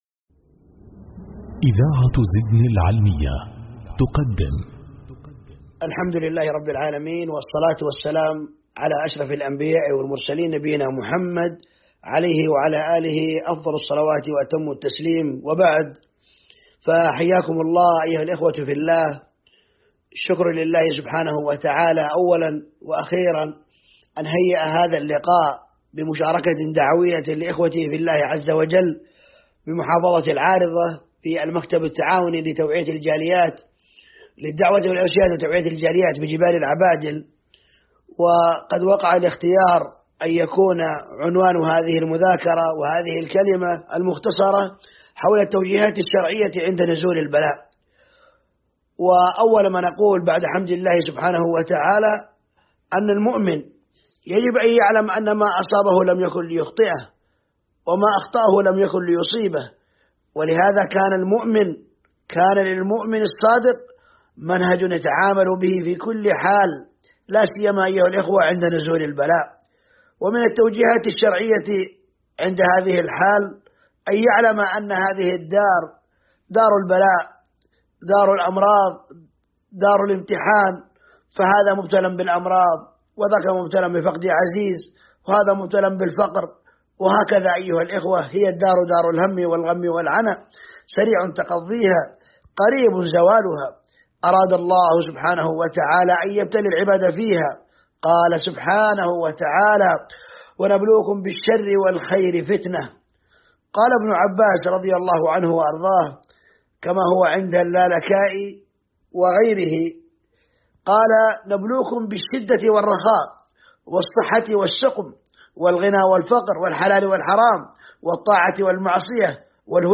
محاضرات وكلمات